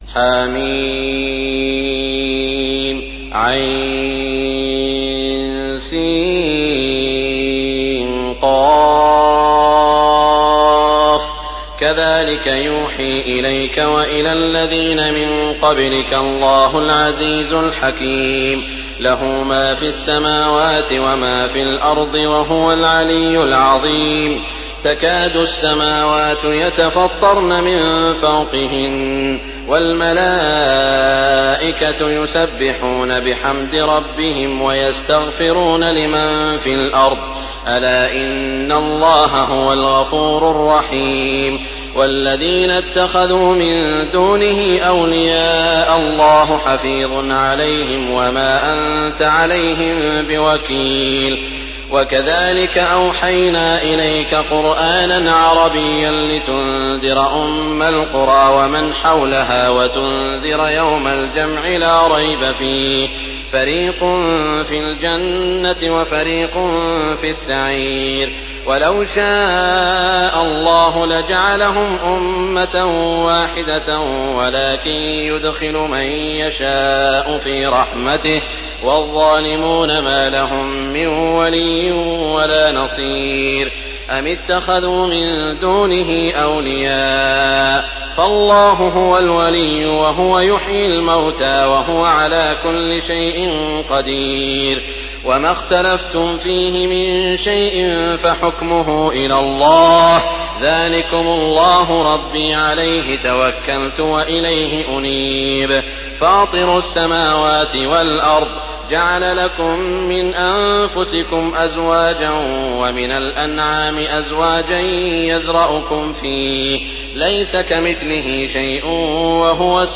تحميل سورة الشورى بصوت تراويح الحرم المكي 1424
مرتل